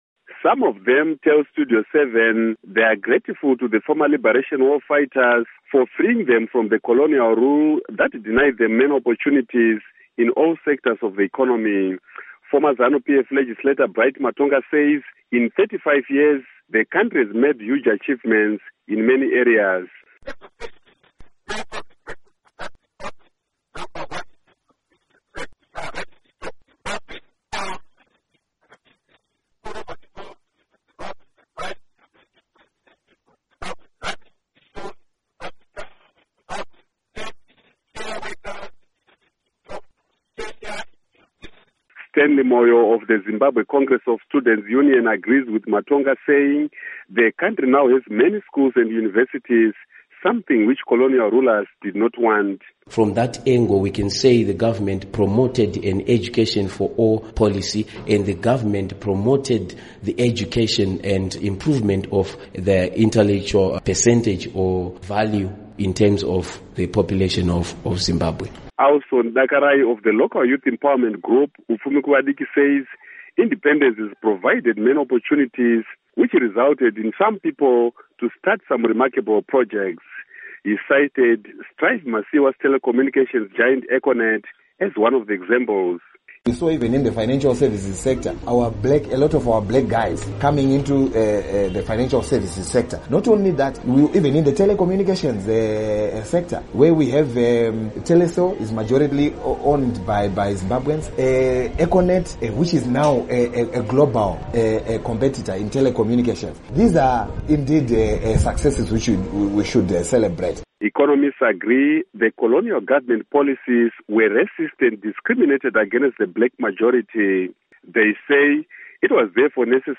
Report on Independence Commemorations